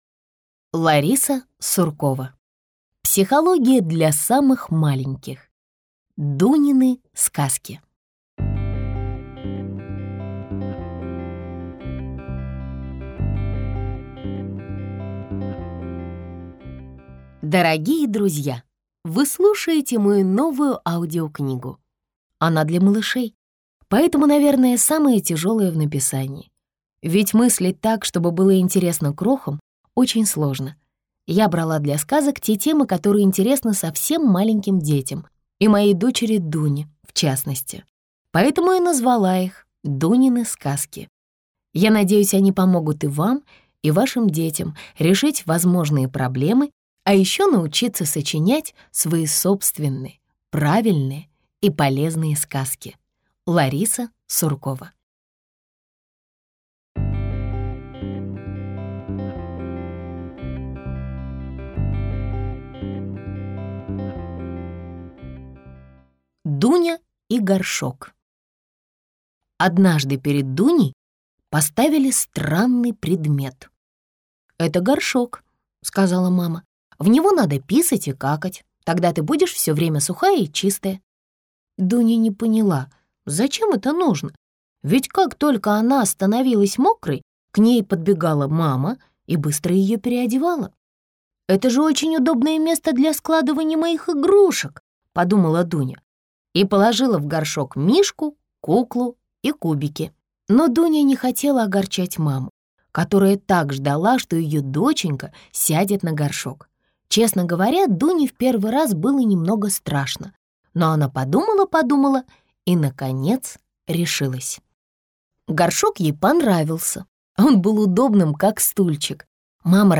Аудиокнига Психология для самых маленьких.